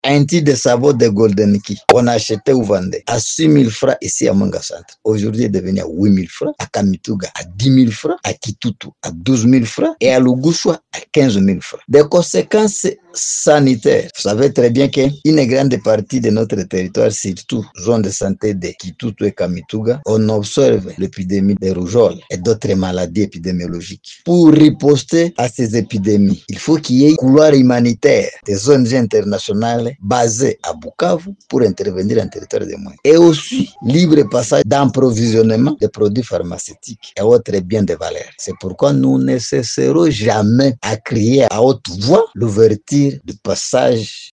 L’alerte est de la société civile territoriale de MWENGA dans un entretien avec Radio MAENDELEO Mardi 14 Octobre 2025.